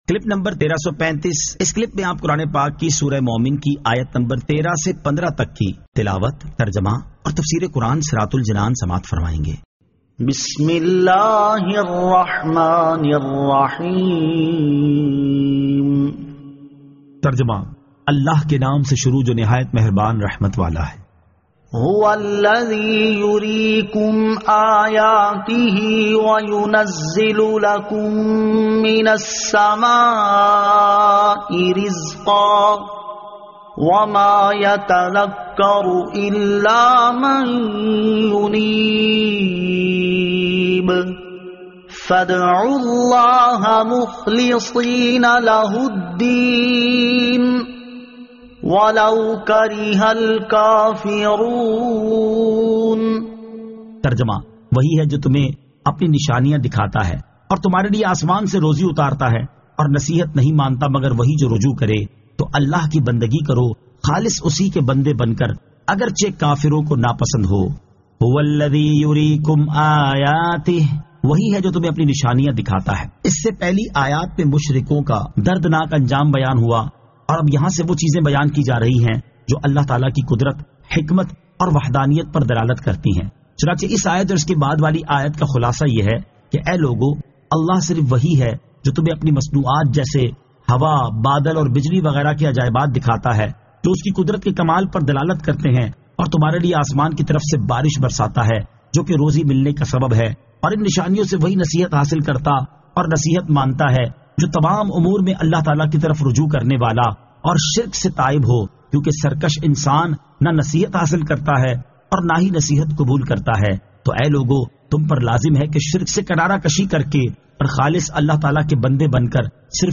Surah Al-Mu'min 13 To 15 Tilawat , Tarjama , Tafseer